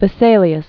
(vĭ-sālē-əs, -zā-), Andreas 1514-1564.